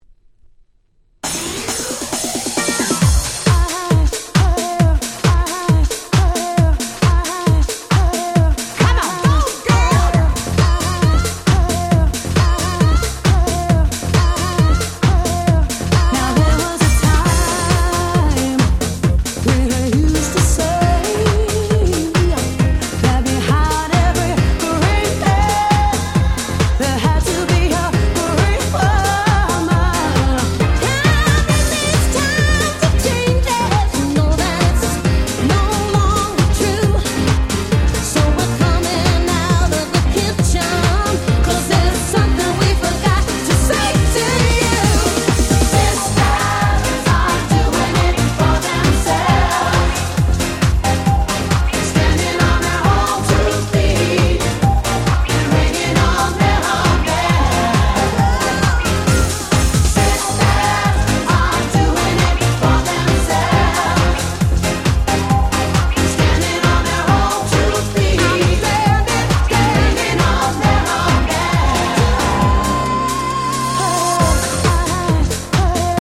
【Media】Vinyl 12'' Single (Promo)